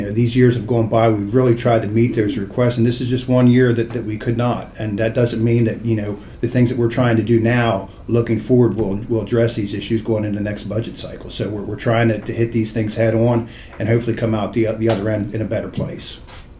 Many of the line items in Allegany County’s budget saw cuts this year including EMS, Allegany College of Maryland, and the county library system.  During the State of the County presentation on Tuesday, Allegany Commission President Dave Caporale said that making decisions to not fully fund county-wide programs has been difficult but staff are working to make things better in the future…